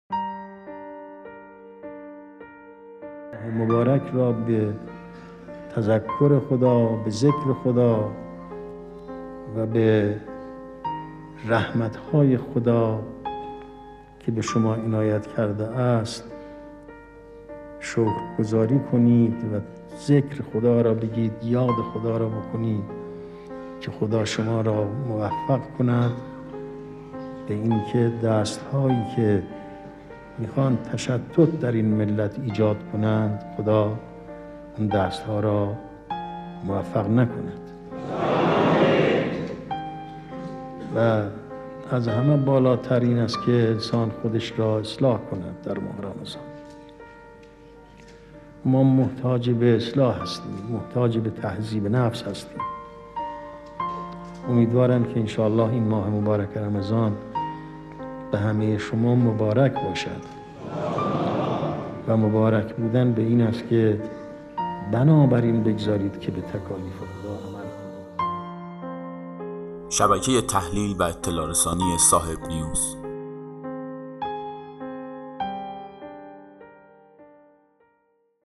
امام خمینی (ره) در بیانی با اشاره به لزوم شکرگذاری از رحمت‌های الهی در ماه رمضان، به معنای مبارک بودن این ماه اشاره کردند.